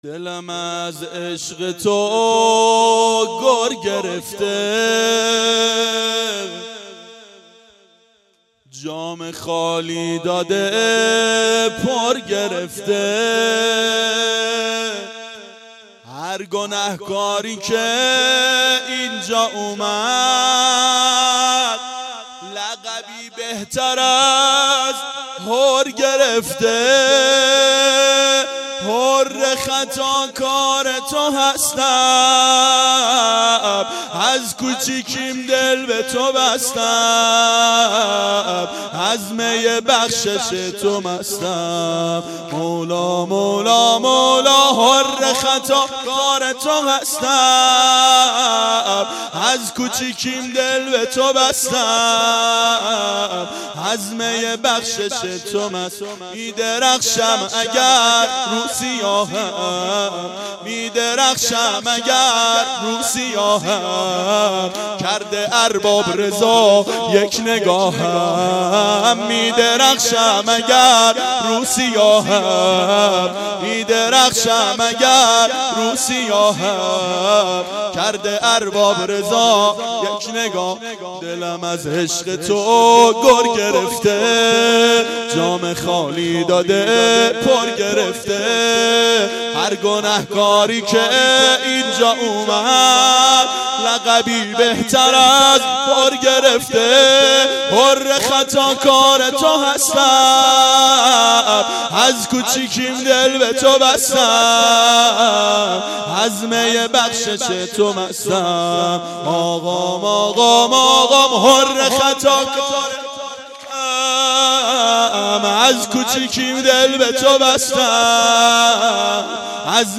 شب میلاد امیرالمؤمنین حضرت علی علیه السلام 93